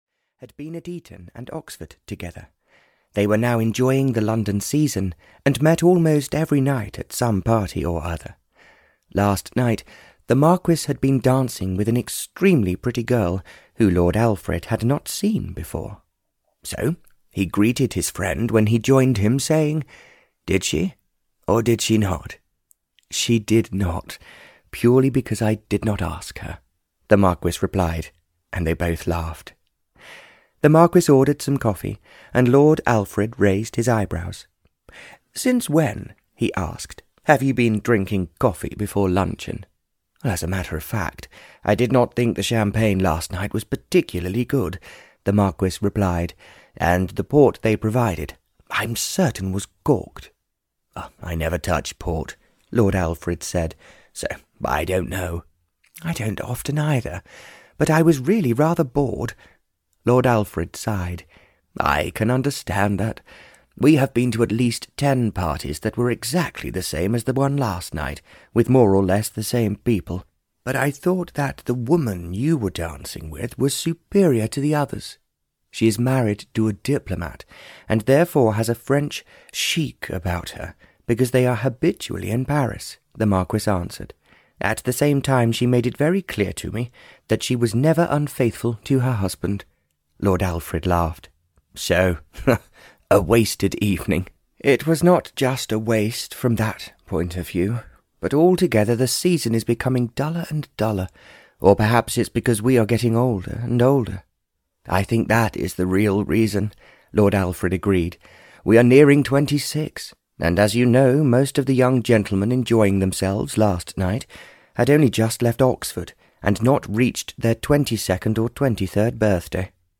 A Road to Romance (Barbara Cartland’s Pink Collection 112) (EN) audiokniha
Ukázka z knihy